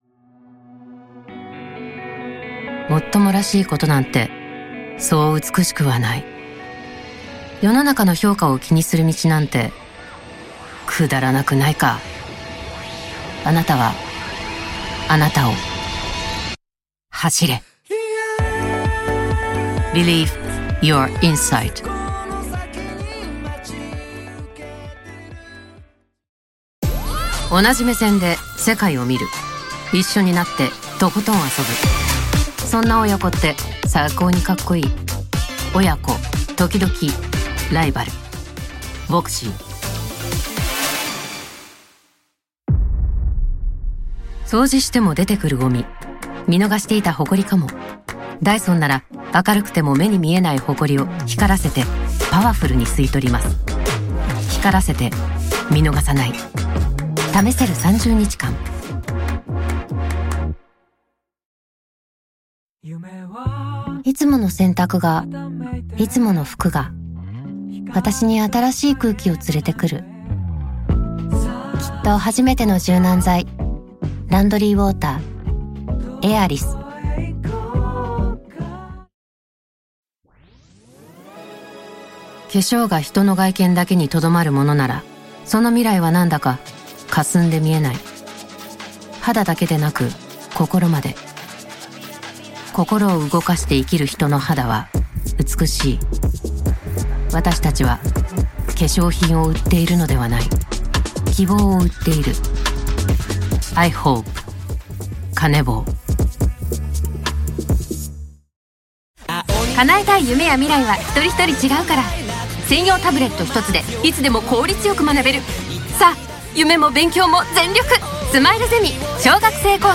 Experto
Confiable
Seguro